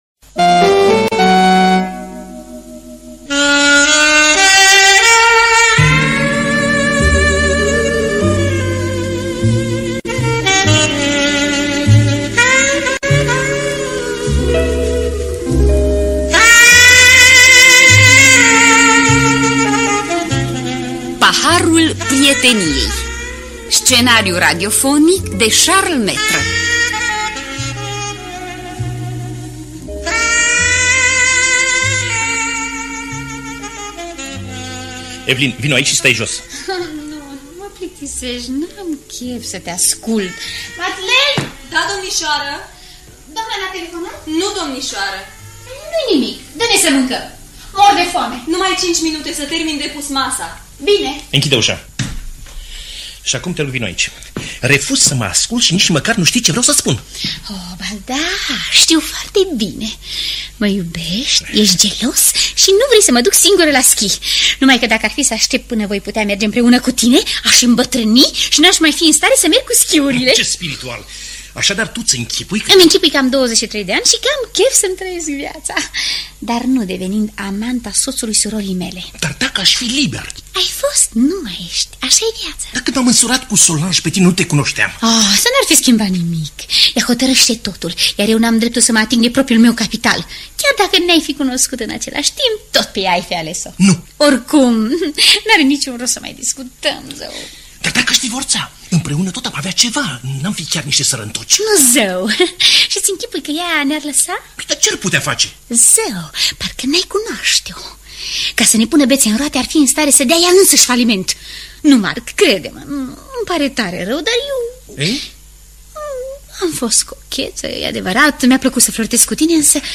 Paharul prieteniei de Charles Maître – Teatru Radiofonic Online